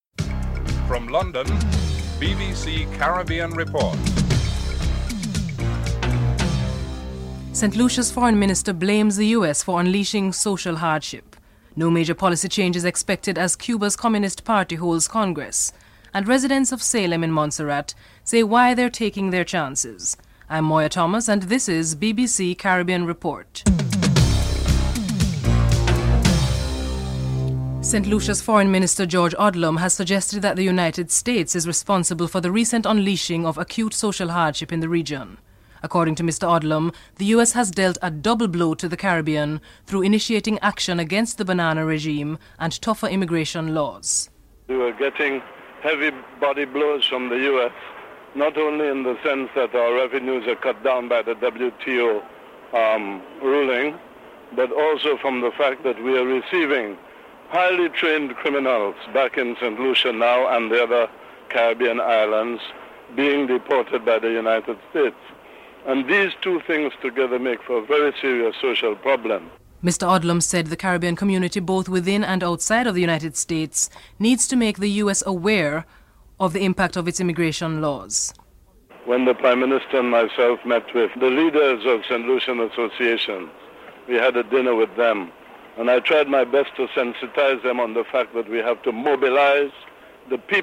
1. Headlines (00:00-00:26)
2. St. Lucia's Foreign Minister blames the United States for unleashing social hardship into the region. St. Lucia's Foreign Minister George Odlum is interviewed (00:27-01:53)